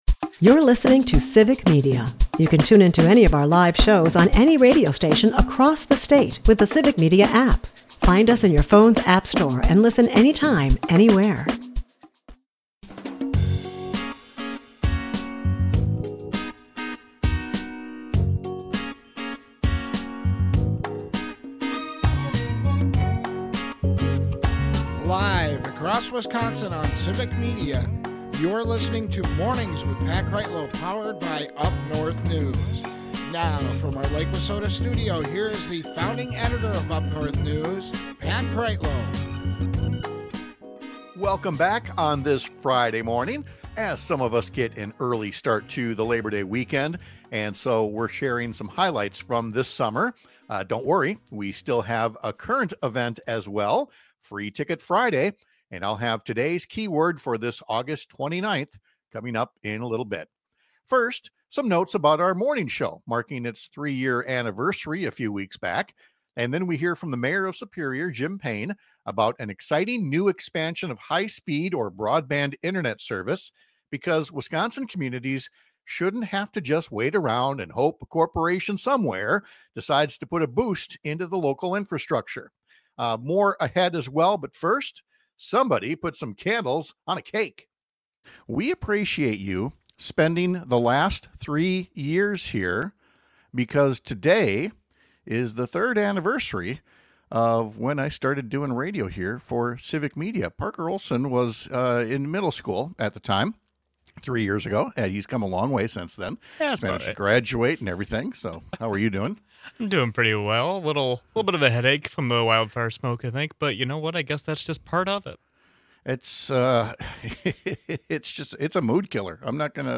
We relive some of the greatest interviews and moments from the summer of 2025